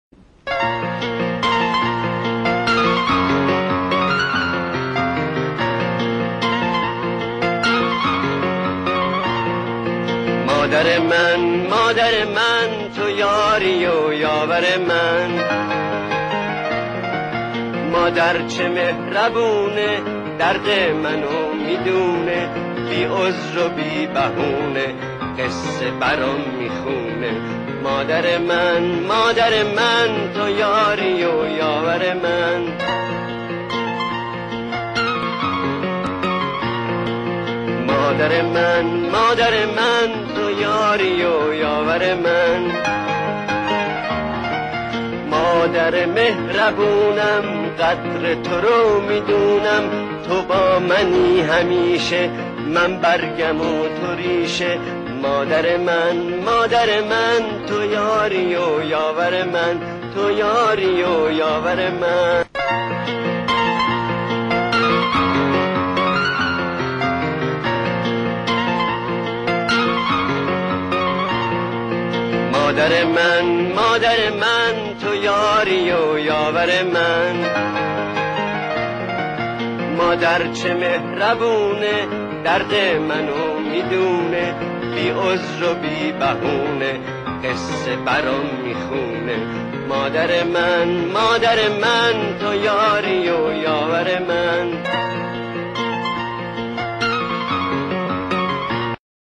آهنگ قدیمی خاطره انگیز
تیتراژ فیلم سینمایی